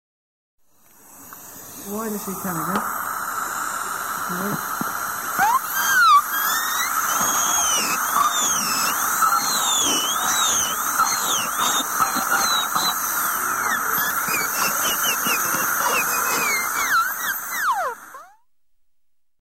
سوراخ کردن دندان با مته ی دندان پزشکی با سرعت بالا
• سوراخ کردن دندان با مته ی دندان پزشکی
27-AT-THE-Dentist-High-Speed-Drilli.mp3